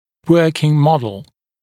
[‘wɜːkɪŋ ‘mɔdl][‘уё:кин ‘модл]рабочая модель